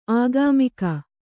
♪ āgāmika